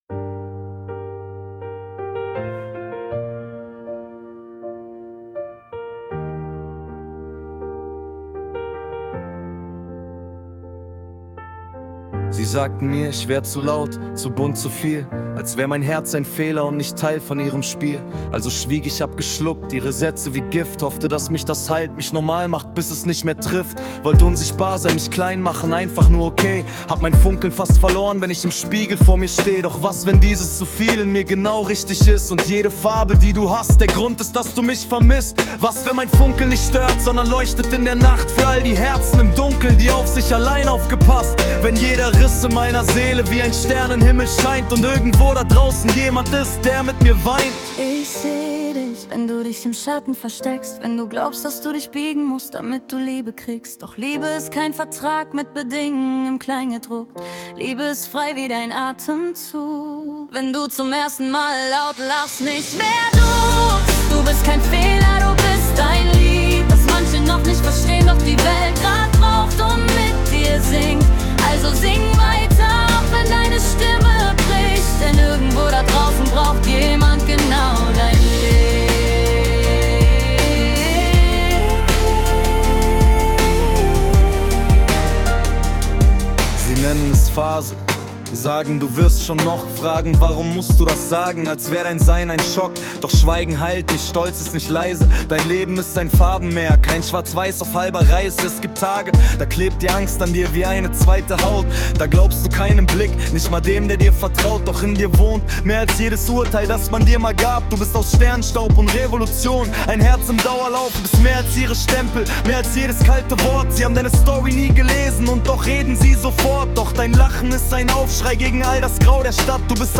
„Du bist ein Lied“ ist ein tiefgehender, empowernder Song über Identität, Selbstannahme und den Mut, anders zu sein – geschrieben aus meiner eigenen Erfahrung als Transperson und Teil der ABDL-Community.